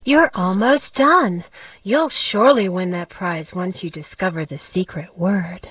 Sound: Listen to Klio (a Muse!) tell you the instructions (06").